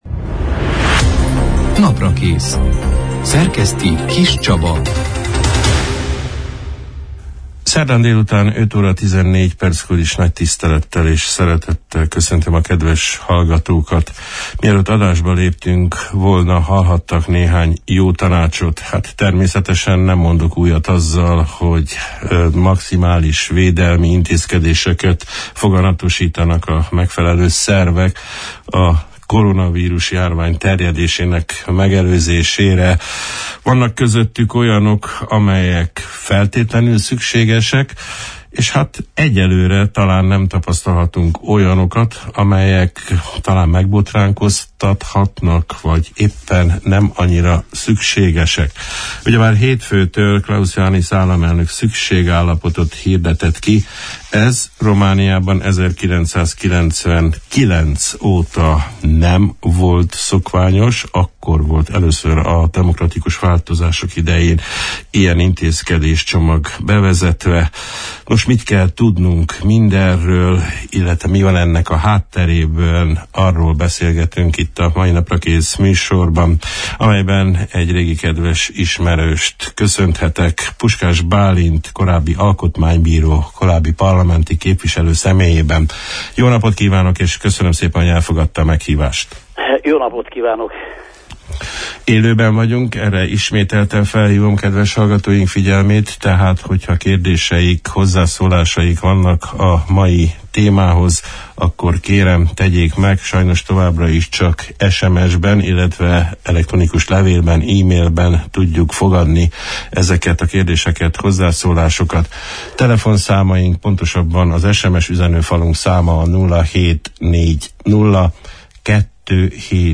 Az államfő által hétfőn kihírdetett szükségállapot jogosságáról, arról, hogy mit is kell tudni minderről, mennyire jogosak az egyes, emberi jogokat is korlátozó határozatok hátteréről, nos ezekről a kérdésekről beszélgettünk a március 18 – án, szerdán délután elhangzott Naprakész műsorban, Puskás Bálint volt alkotmánybíróval.